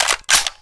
m4a1_boltpull.mp3